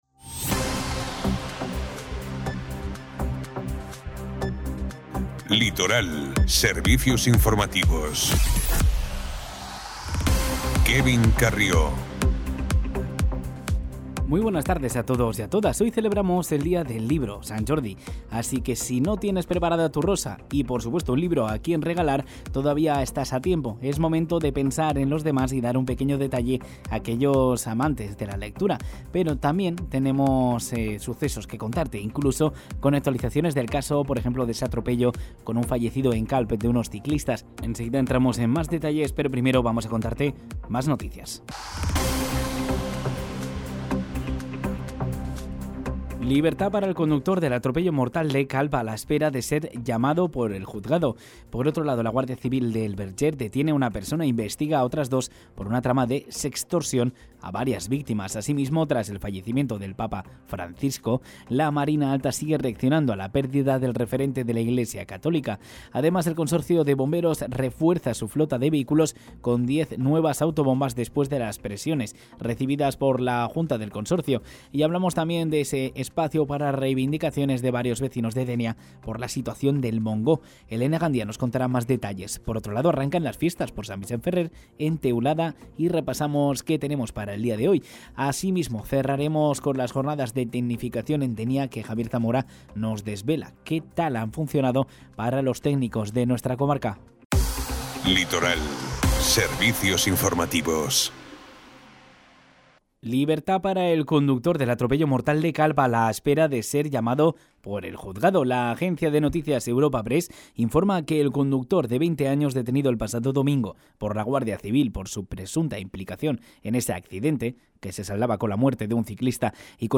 Informativo Ràdio Litoral 23/04/2025 | Ràdio Litoral